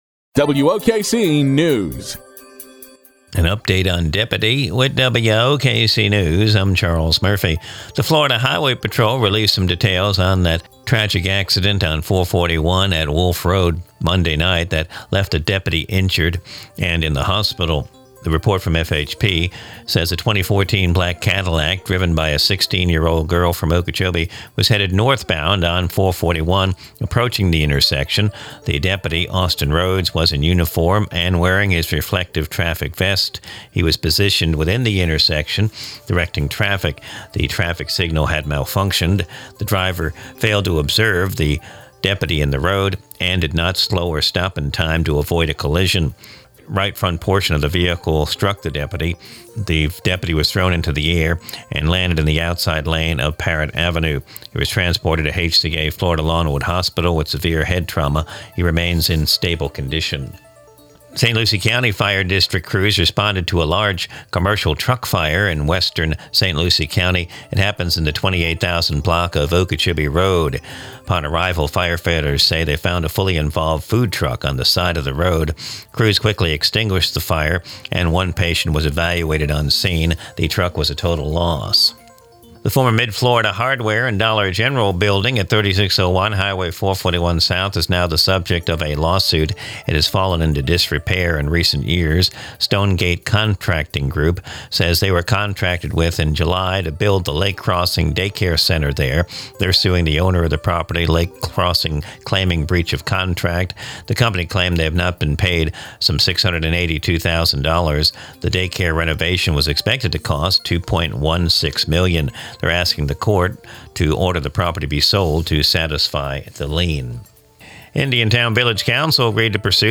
Recorded from the WOKC daily newscast (Glades Media).